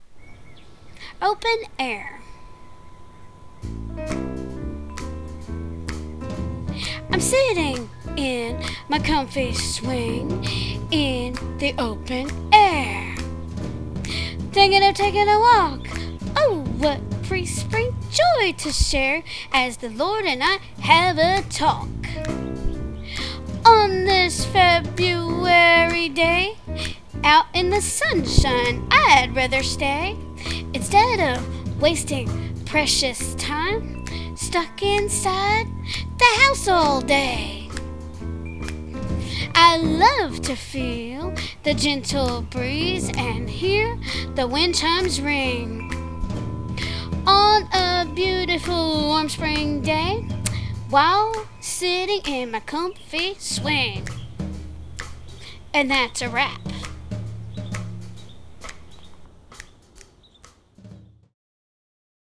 So naturally I had to put this to some music, plus the outdoorsy soundscape you hear. I re-recorded it along with a jazzy tune I had originally arranged for something else, but it happened to fit the poem perfectly.